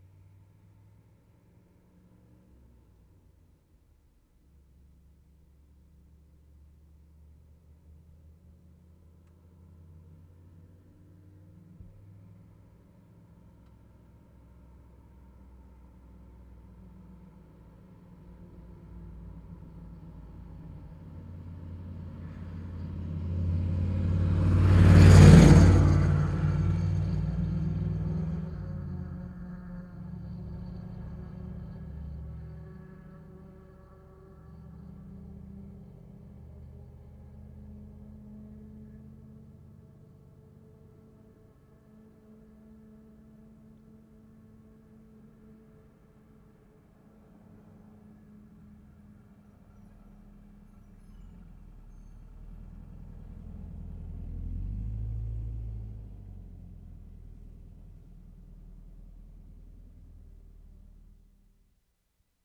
WORLD SOUNDSCAPE PROJECT TAPE LIBRARY
ST. LAURENT - NORTH SHORE, QUEBEC Oct. 26, 1973
TRUCK PASSING 1'00"
12. Good approach and disappearance because of quiet ambience.